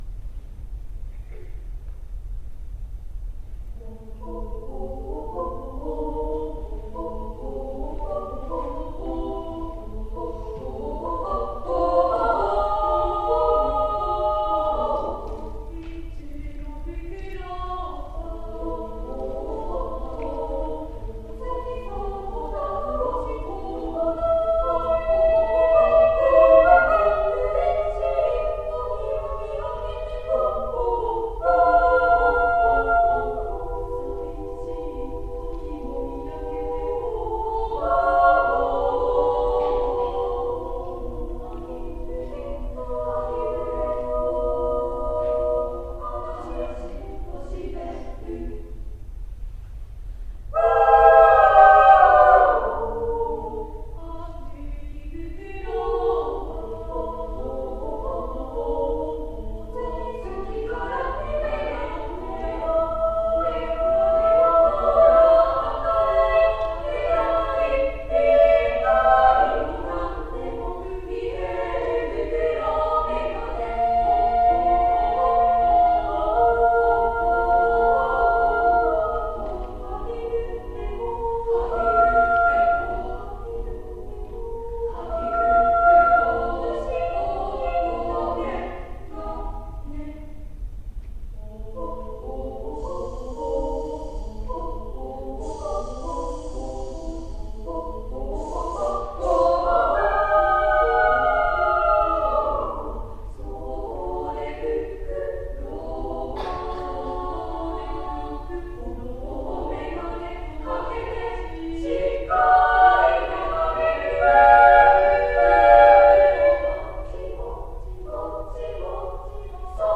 第31回三重県合唱アンサンブルコンテスト／合唱部Ｂ『ふくろうめがね』
コンテストの期日は２月２日（日）、会場は鈴鹿市民会館でした。
簡易的な録音機器による収録のため、音質があまりよくないことをお許しください。
※１年生中心の10名による同声４部の合唱